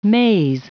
Prononciation du mot maize en anglais (fichier audio)
Prononciation du mot : maize